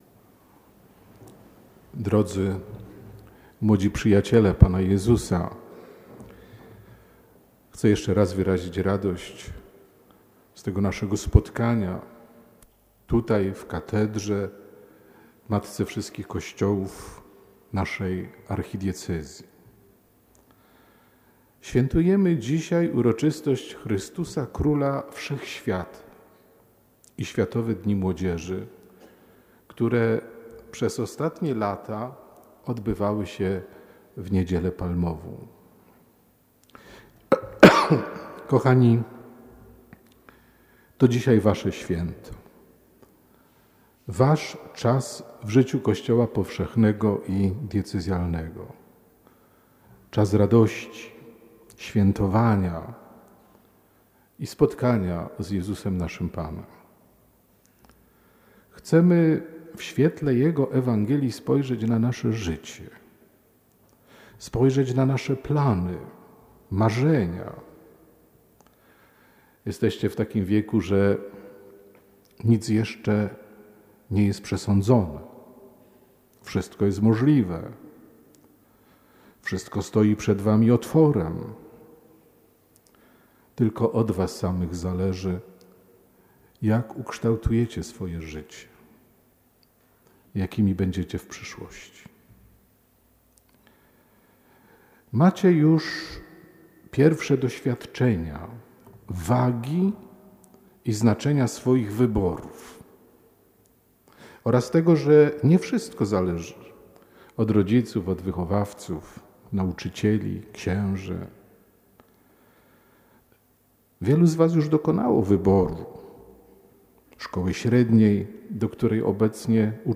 homilia-abp.mp3